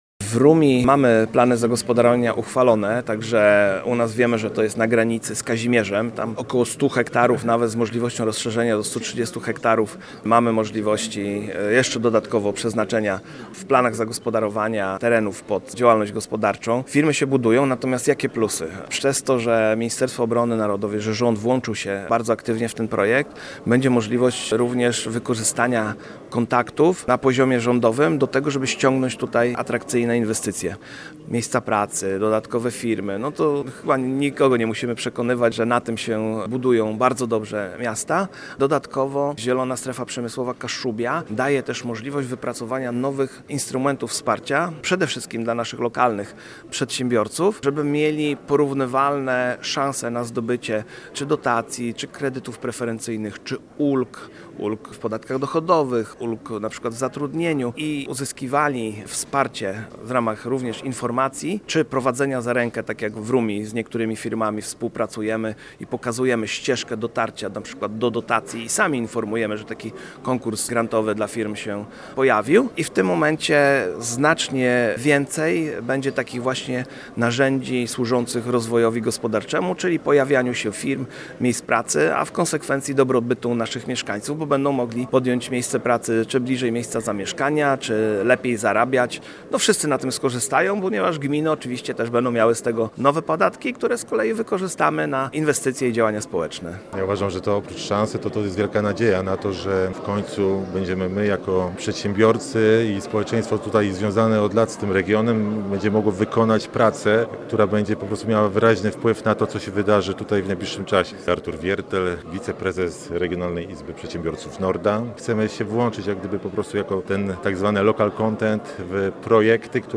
Posłuchaj materiału naszego reportera: https